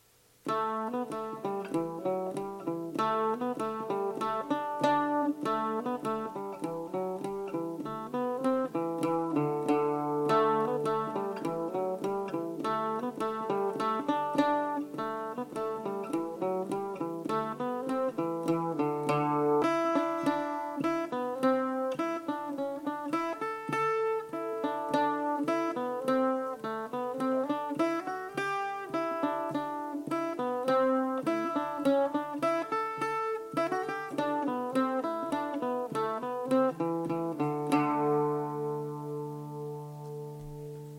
Note that on the version below, I removed most of the triplets to keep it simple, but by all means if you’re up for it, add them in from the sheet music I handed out in class.
Slower:
Ballydesmond 1 (Key of Am)